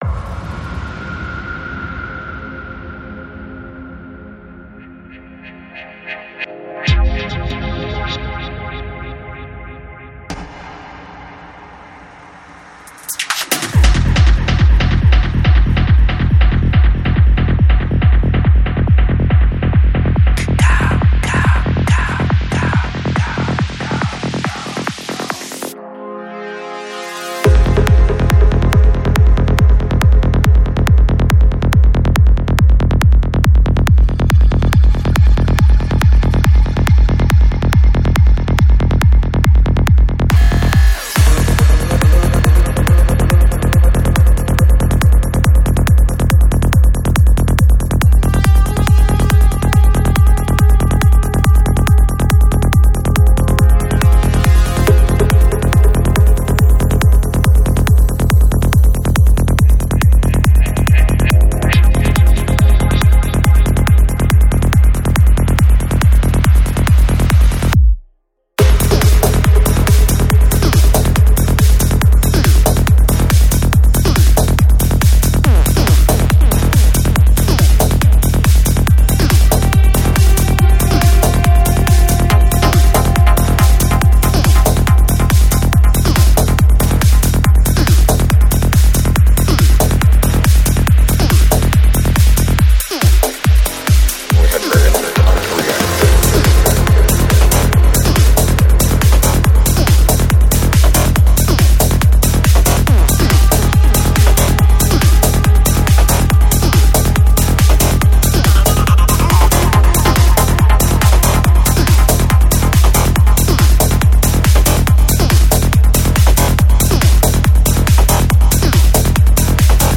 Жанр: Psychedelic Rock
Psy-Trance